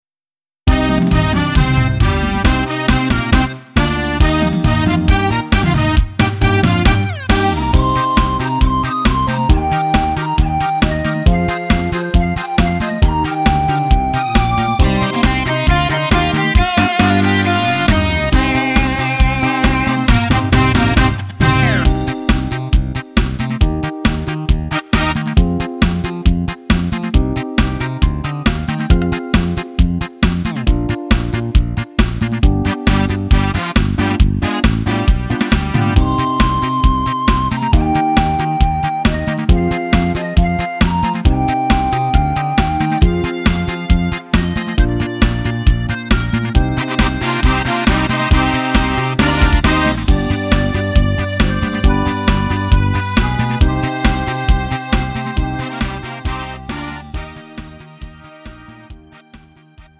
음정 원키 3:19
장르 구분 Lite MR